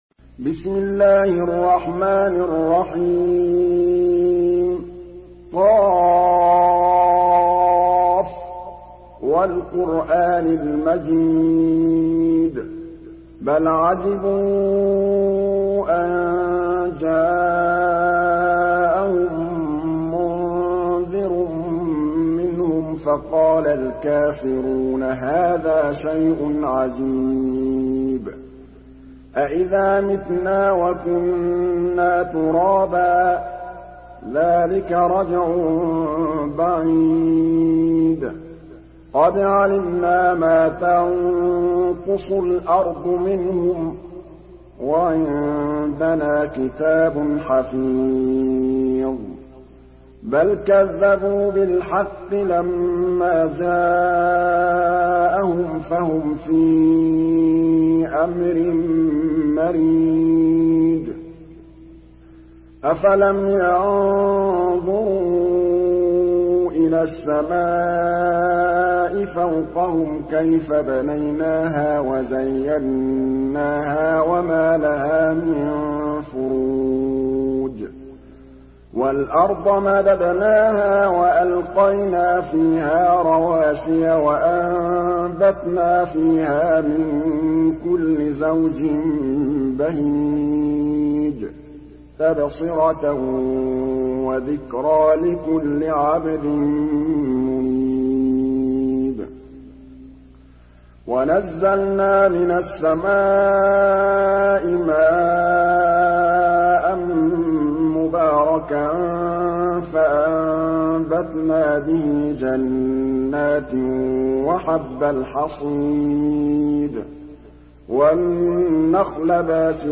Surah ق MP3 by محمد محمود الطبلاوي in حفص عن عاصم narration.
مرتل حفص عن عاصم